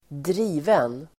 Uttal: [²dr'i:ven]